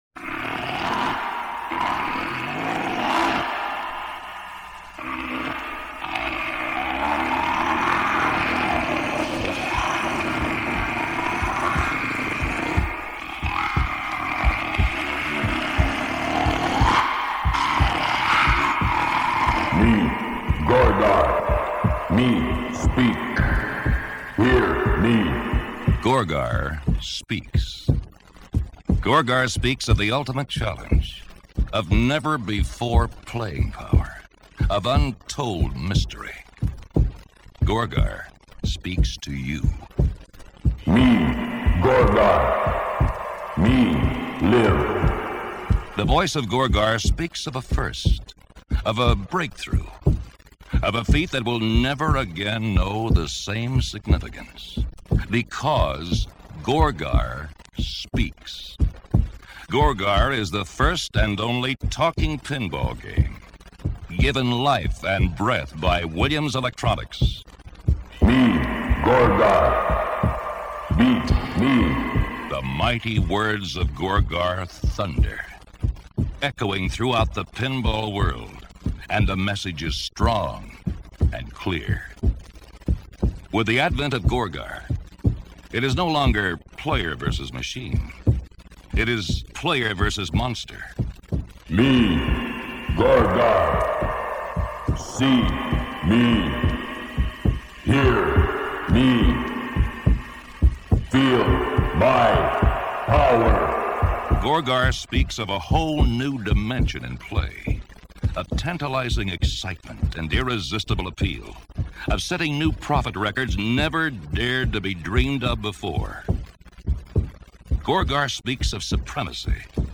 Beh, il "Gorgar" e' il primo flipper parlante ad essere stato prodotto in larga scala, quindi la scelta e' obbligata! Nel depliant promozionale del flipper un flexi-disc allegato permetteva di ascoltare una presentazione del flipper ..dalla sua stessa voce!
Ascolta la voce del "Gorgar"...!